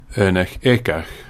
Pronunciation[ˈɯːnəx ˈekəx ˈs̪kɔɾ nəm ˈfian̪ˠɪ]